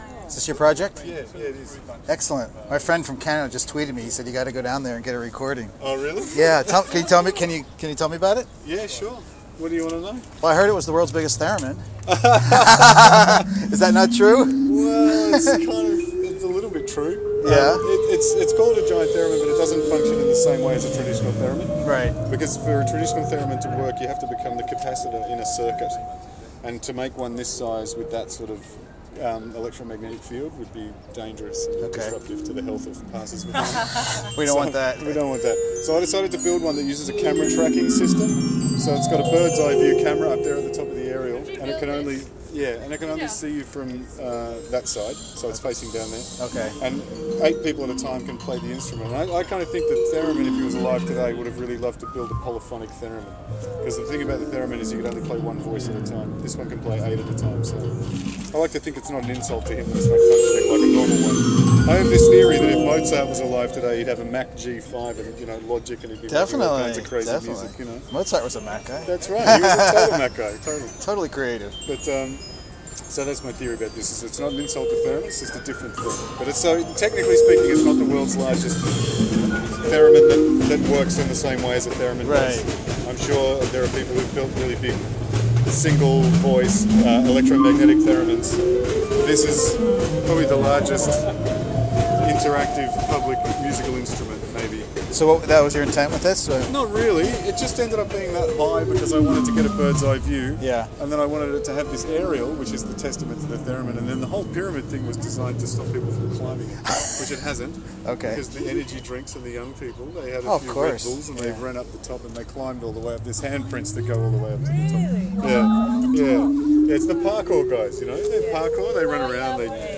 I managed to do a short Q&A recorded to el crappo android phone- he explains that it is not exactly the largest theremin…: